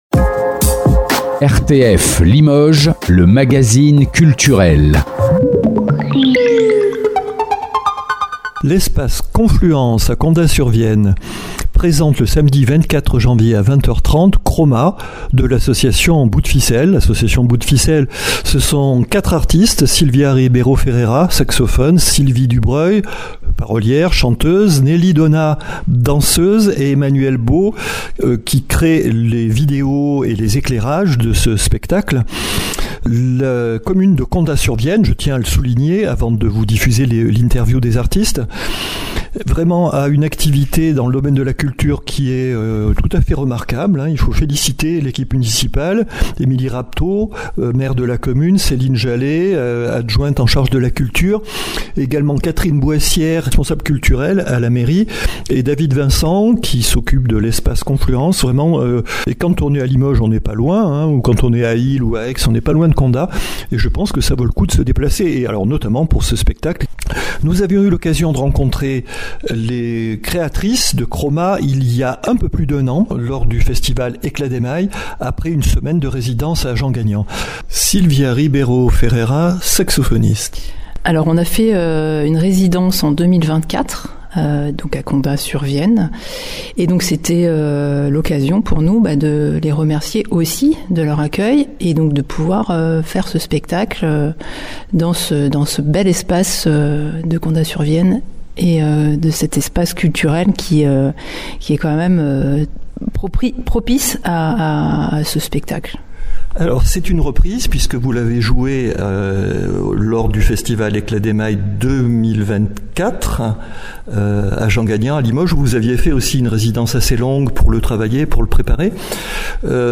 une interview